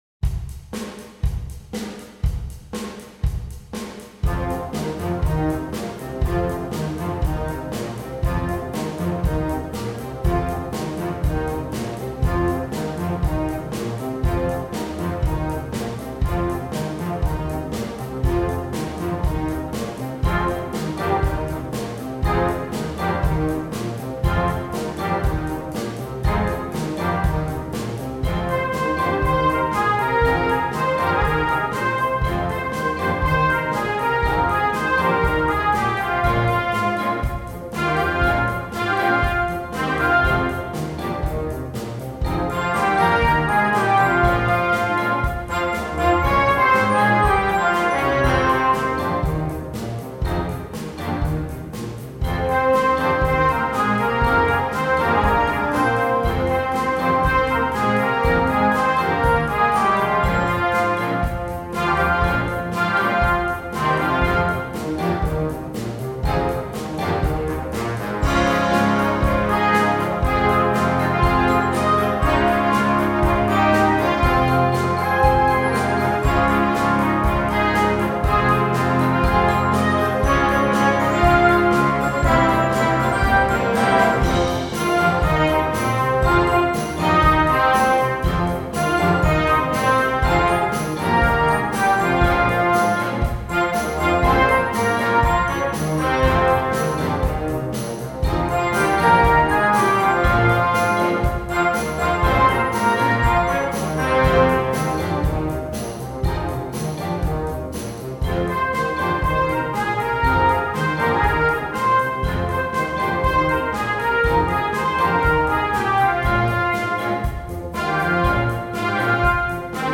Gattung: Moderner Einzeltitel
Besetzung: Blasorchester
dynamisches und kraftvolles Arrangement